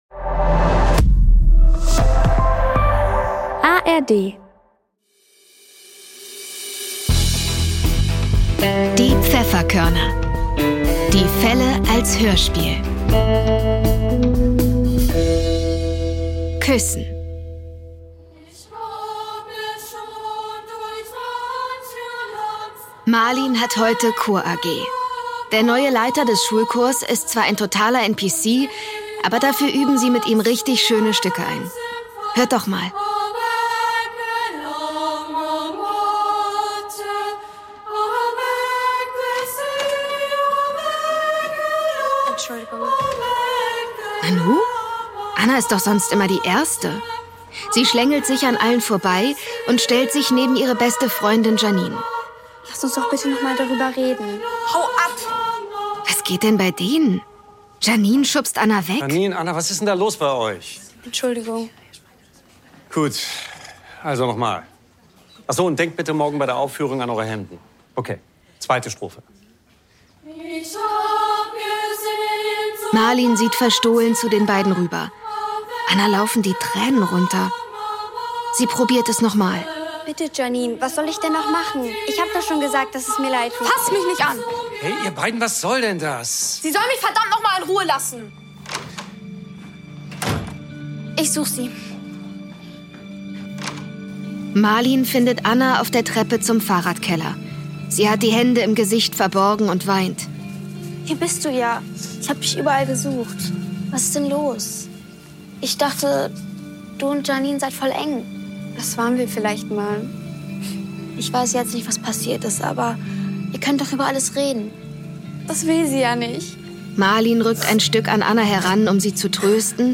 Küssen (10) ~ Die Pfefferkörner - Die Fälle als Hörspiel Podcast